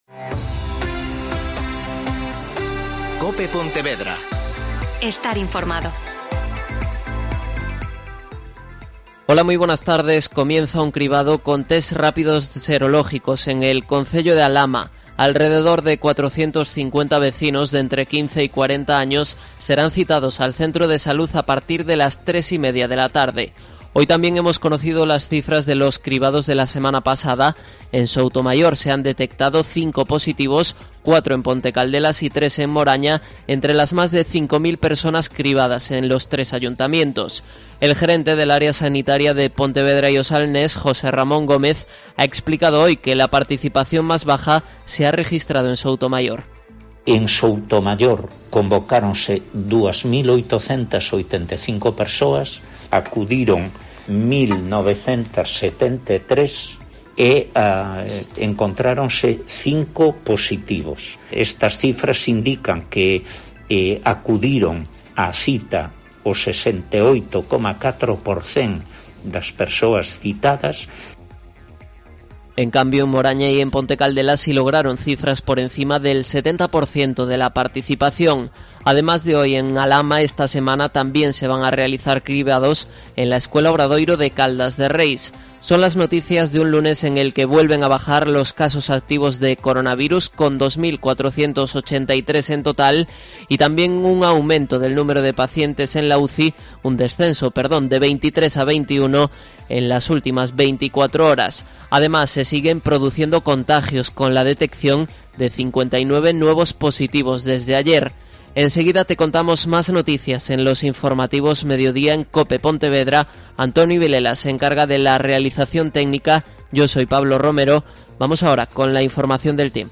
Mediodía COPE Pontevedra (informativo 14:20h)